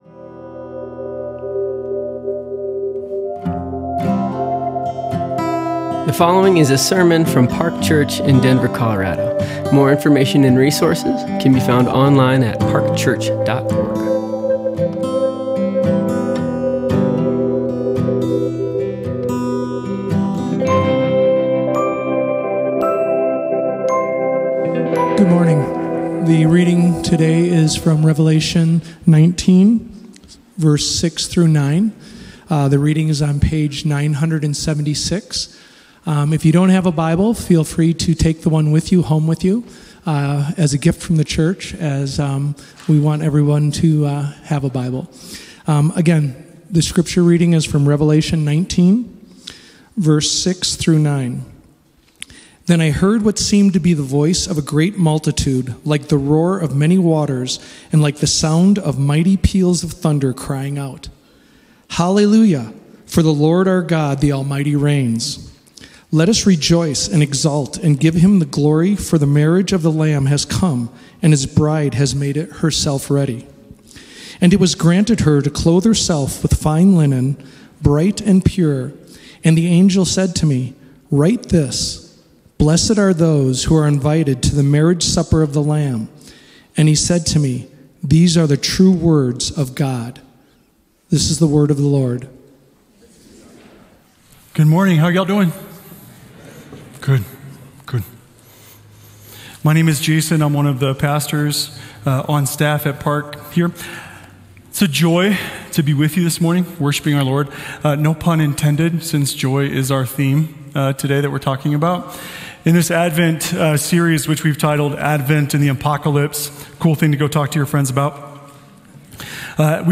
Category Sermons | Park Church